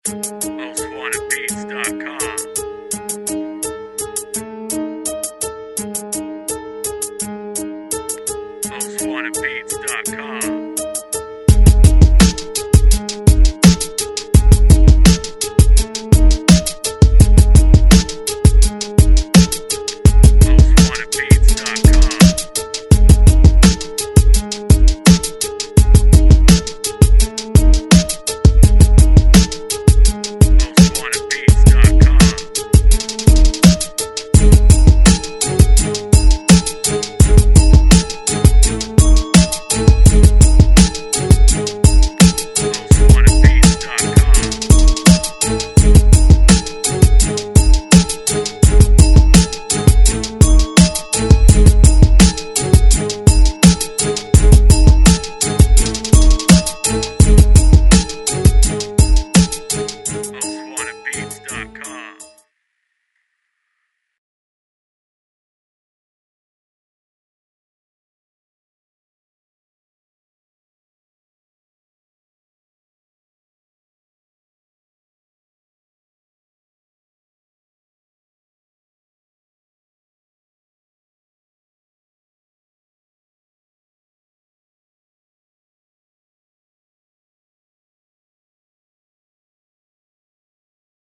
DIRTY SOUTH INSTRUMENTAL